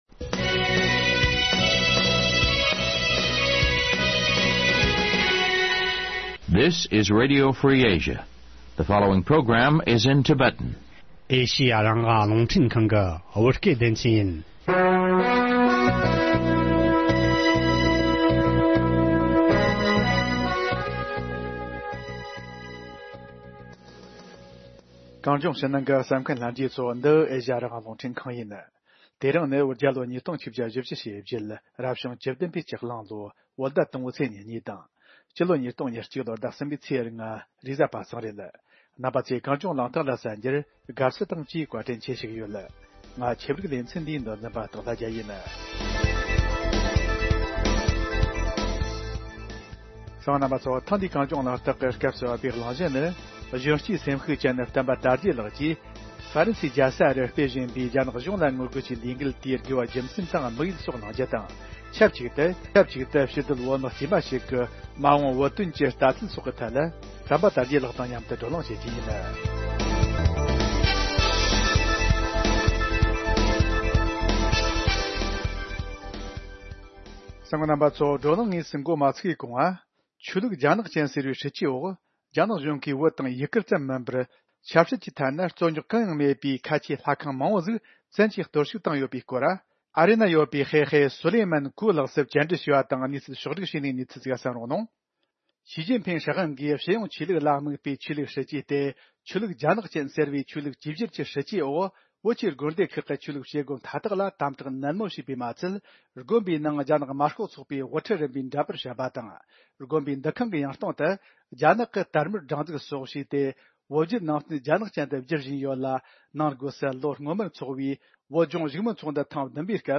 བགྲོ་གླེང་ཞུས་པ་གསན་རོགས་གནང་།